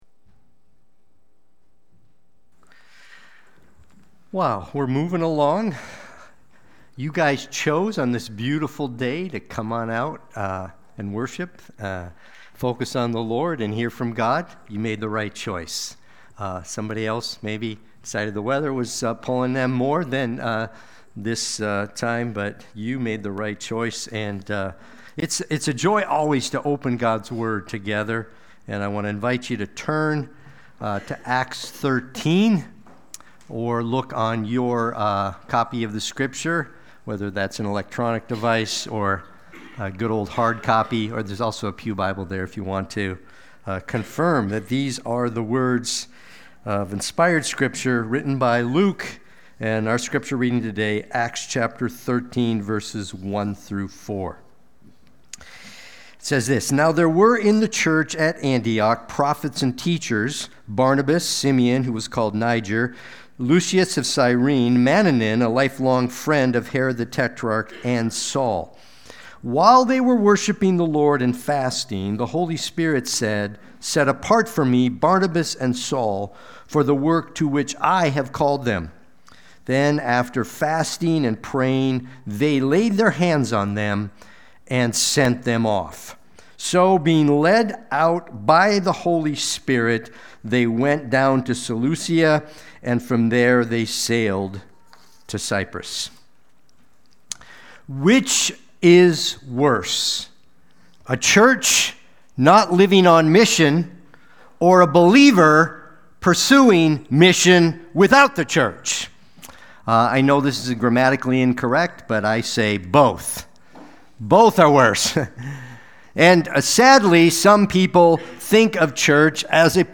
Watch the replay or listen to the sermon.
Sunday-Worship-main-51825.mp3